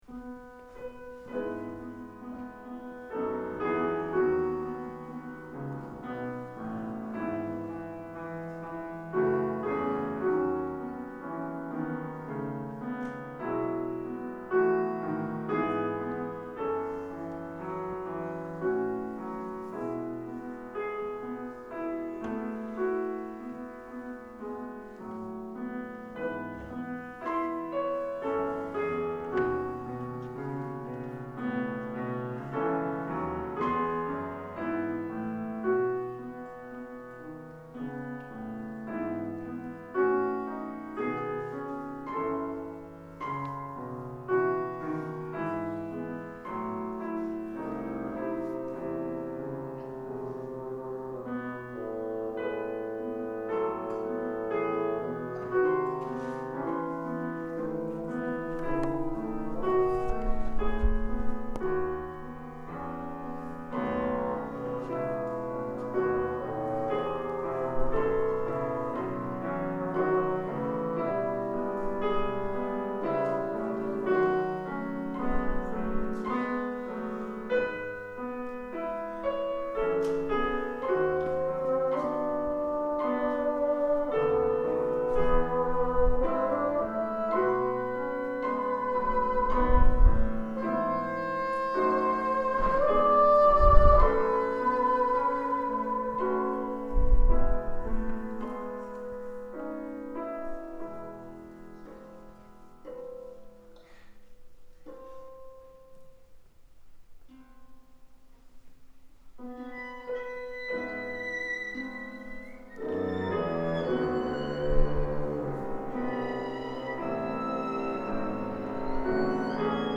Lyt til koncertoptagelse af det 7. hørebillede "Splintret lykke"